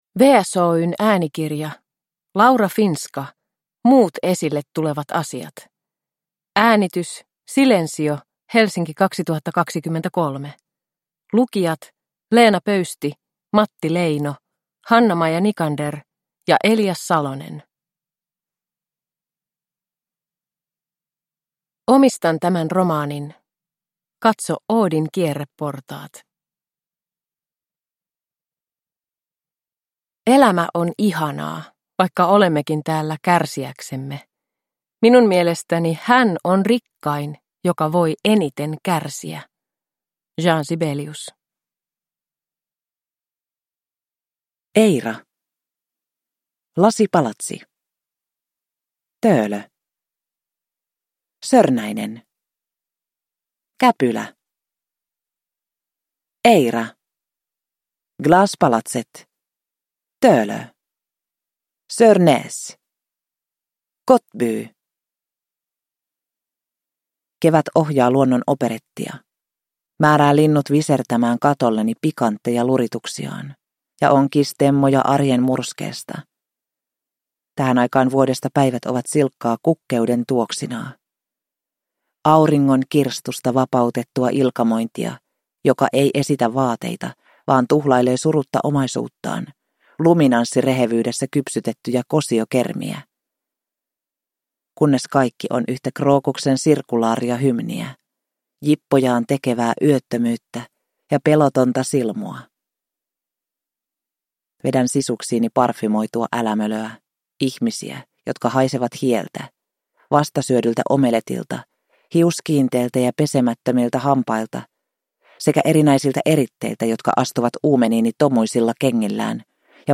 Muut esille tulevat asiat – Ljudbok – Laddas ner